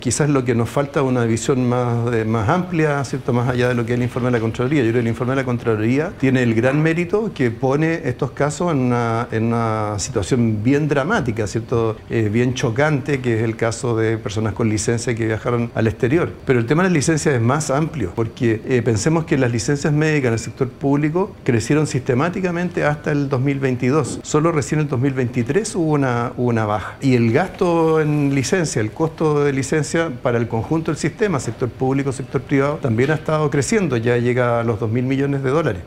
En entrevista con Mesa Central, el ministro indicó que las investigaciones por los casos de licencias mal utilizadas ya deberían haber comenzado.